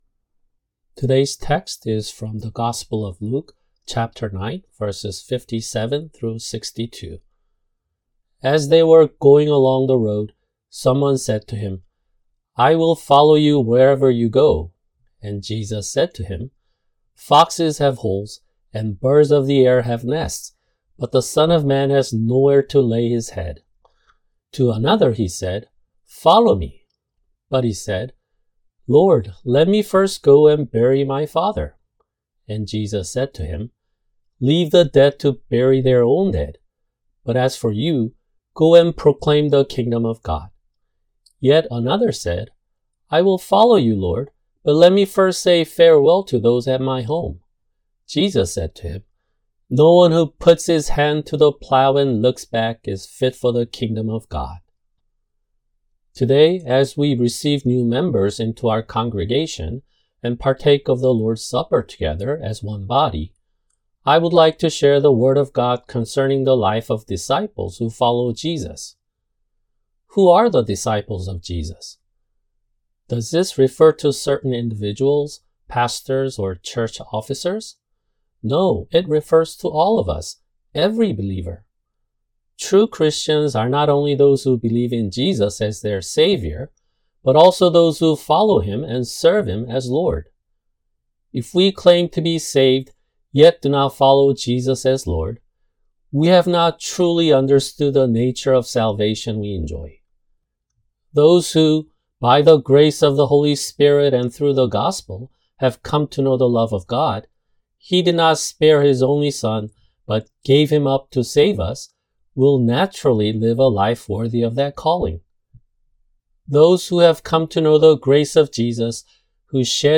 [주일 설교] 민수기 27:12-23
[English Audio Translation] Numbers 27:12-23